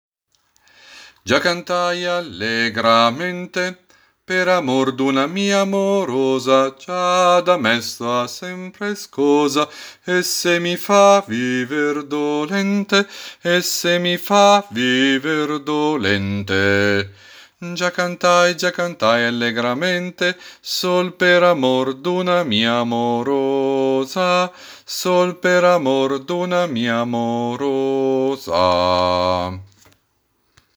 BASSI